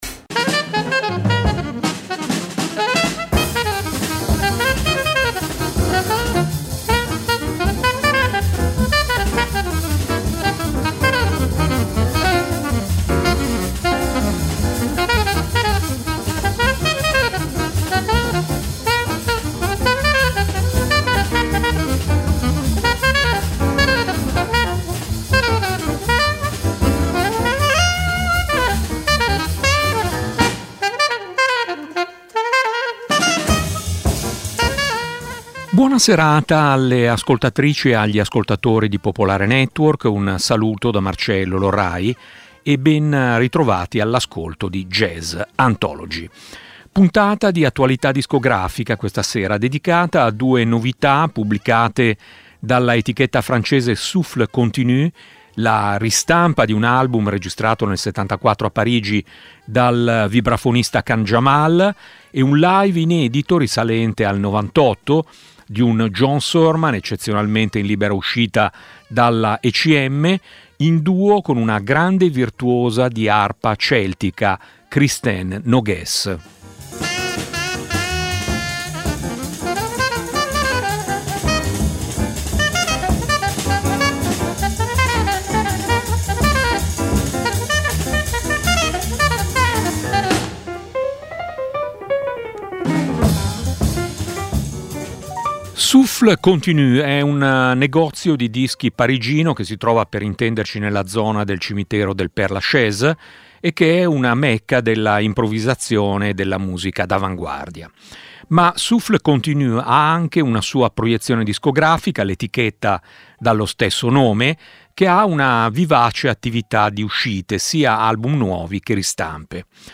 jazz d'avanguardia e musica improvvisata
in registrazioni dal vivo dell'estate del '98
virtuosa dell'arpa celtica